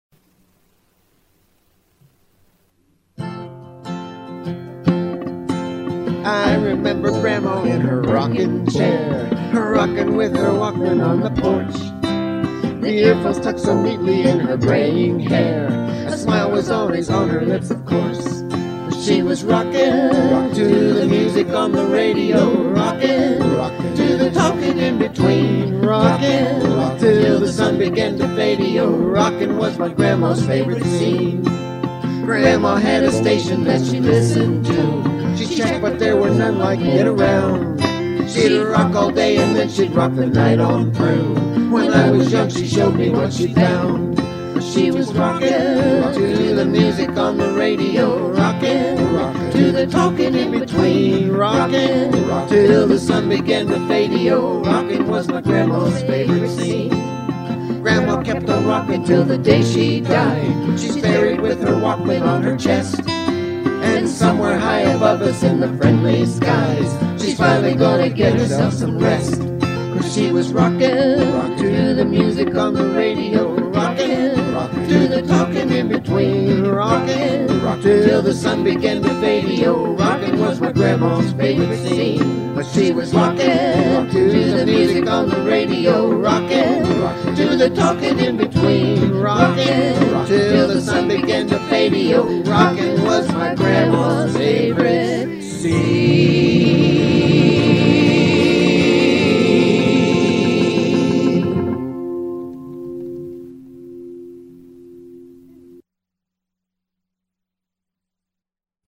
Below are some songs that have not been adequately produced.